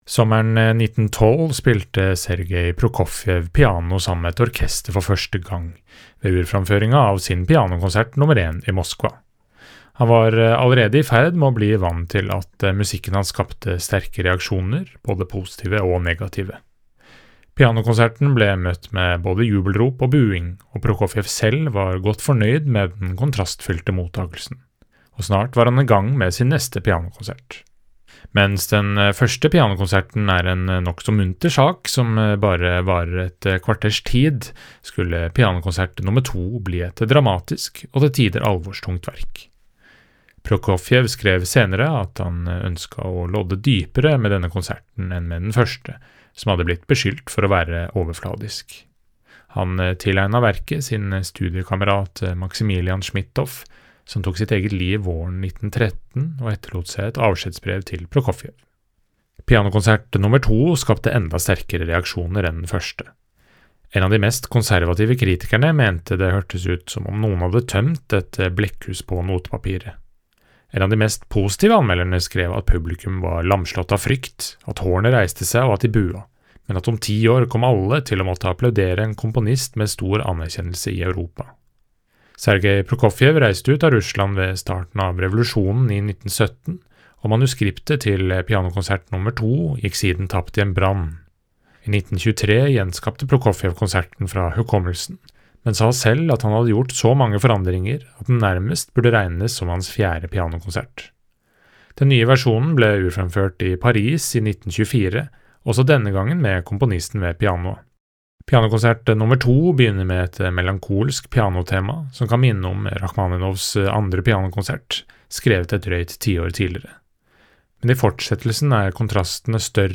VERKOMTALE-Sergej-Prokofjevs-Pianokonsert-nr.-2.mp3